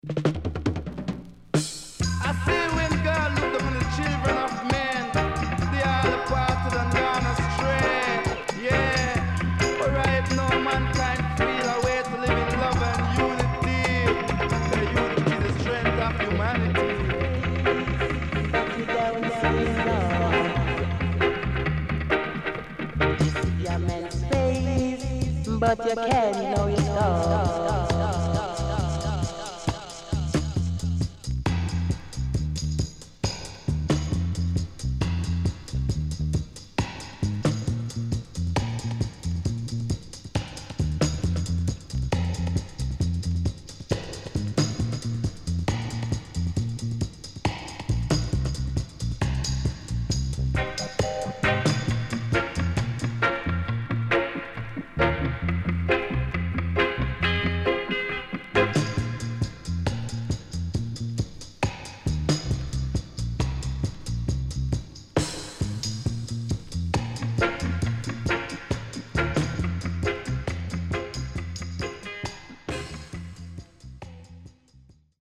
HOME > REGGAE / ROOTS  >  KILLER & DEEP
Deejay Cut & Dubwise
SIDE A:うすいこまかい傷ありますがノイズあまり目立ちません。